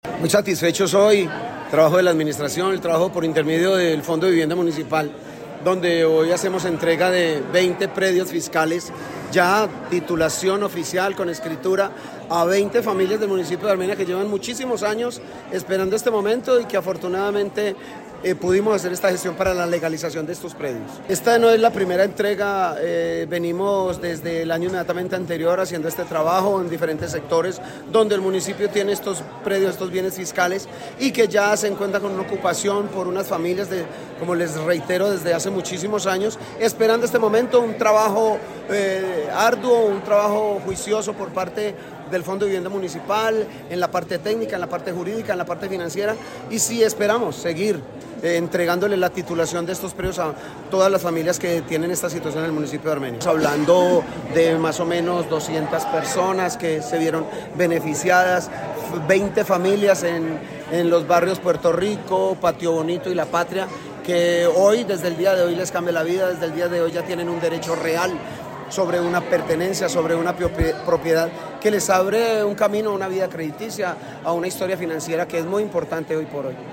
Alcalde de Armenia, James Padilla García, sobre entrega de titulación de predios